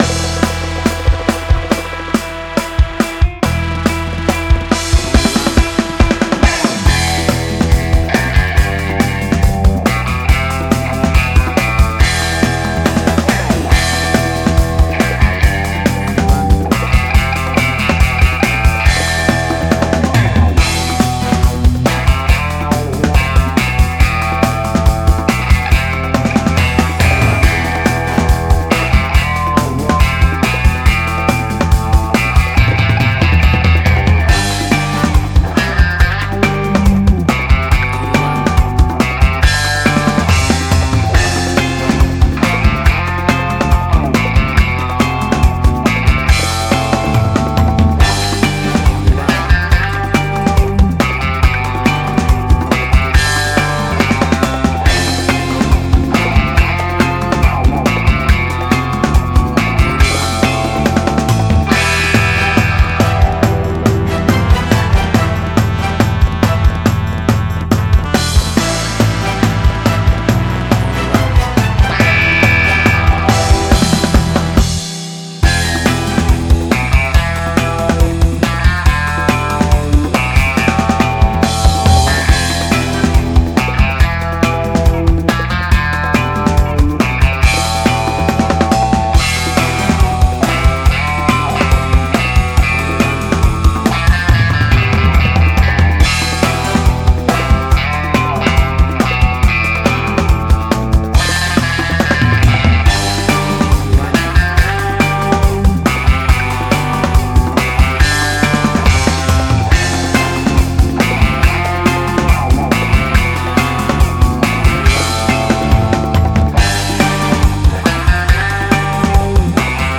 Retro-60s.
Tempo (BPM): 140